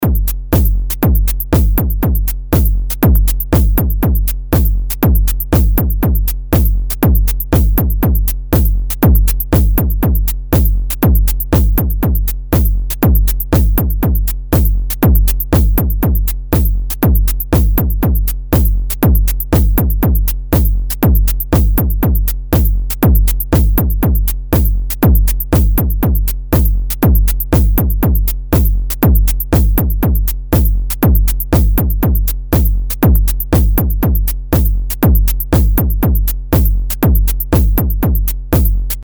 Собственно boss od-3 стоковый без модов. В примерах сырые синтетические барабаны - od3.off.mp3. И обработанные (три положения гейна 0/50/100%) - od3.mp3.